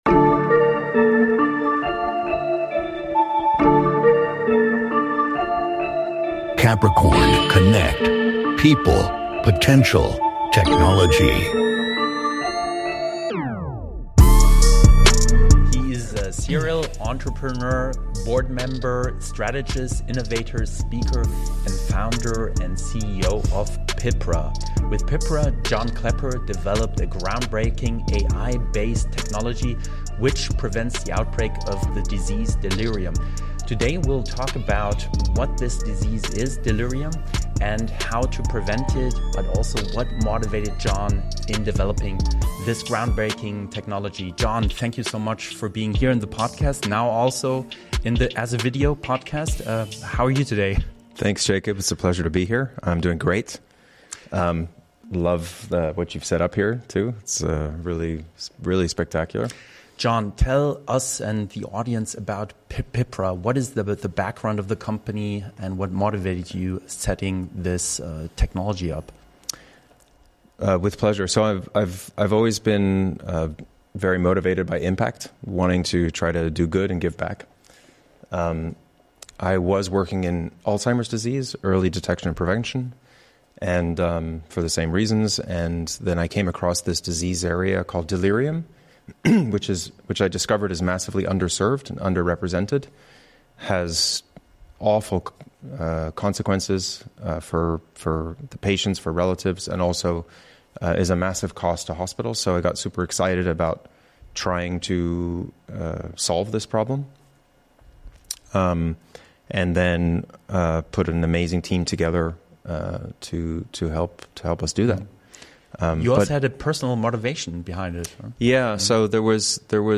#96 - Interview